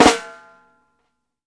FLAM1     -R.wav